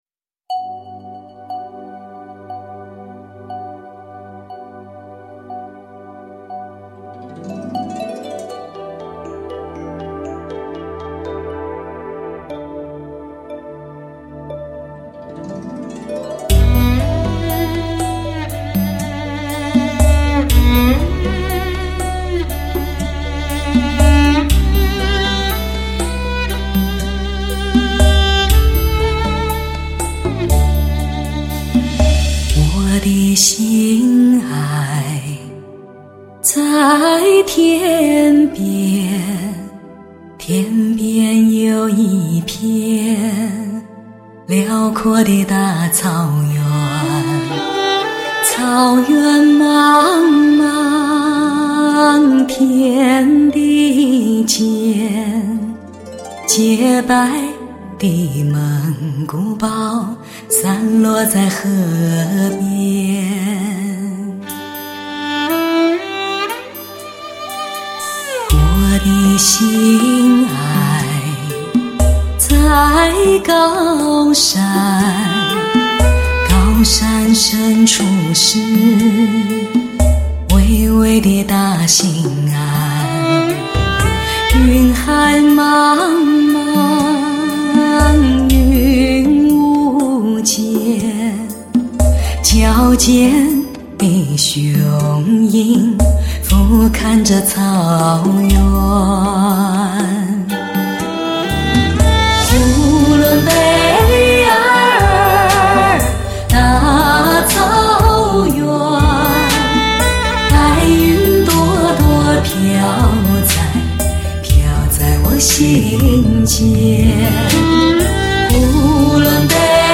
专辑格式：DTS-CD-5.1声道
一张车载音响迷不容错过的测试监听女声碟皇
史上最美原生态女中音